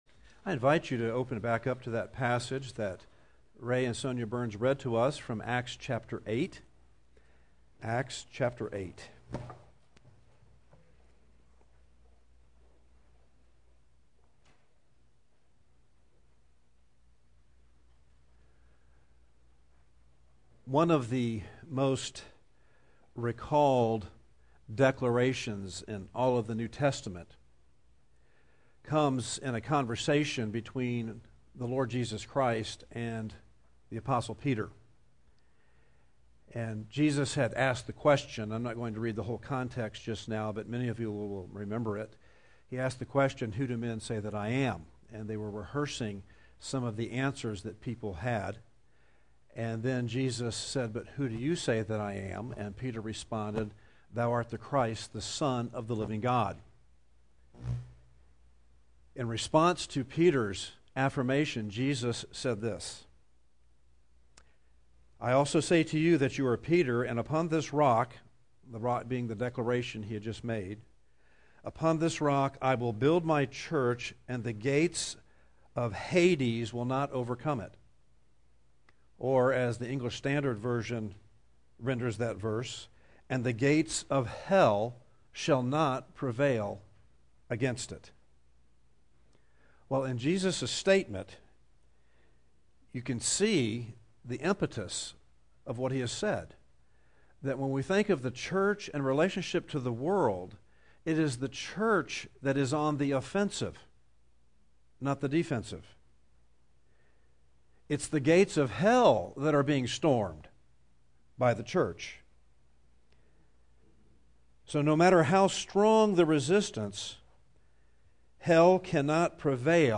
The Gates of Hell Shall Not Prevail » The Chapel Church of Gainesville, Florida